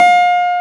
CLAV A4+.wav